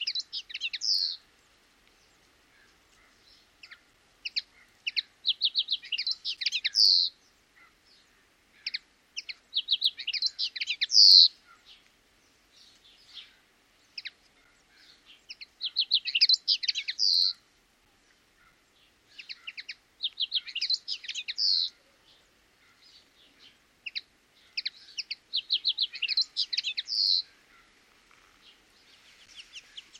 Photos de Linotte mélodieuse - Mes Zoazos
Son gazouillis est agréable et doux, son vol vif et léger.
linotte-melodieuse.mp3